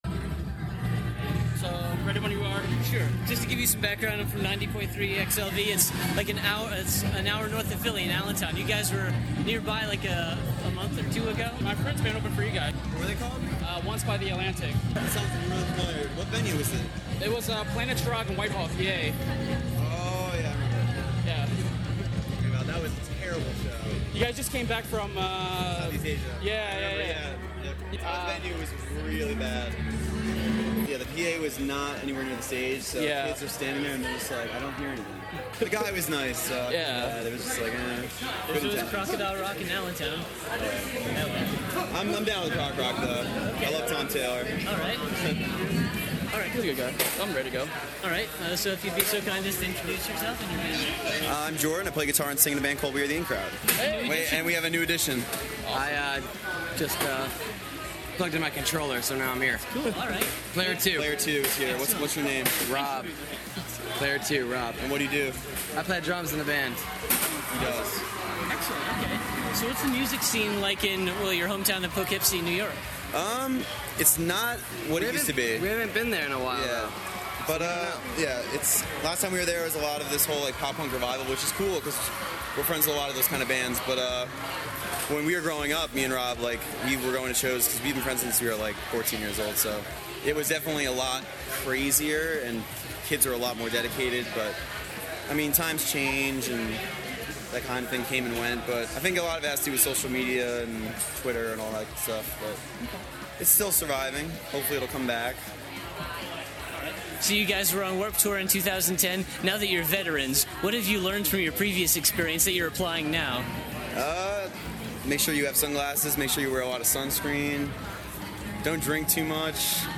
Exclusive: We Are the In Crowd Interview
19-interview-we-are-the-in-crowd.mp3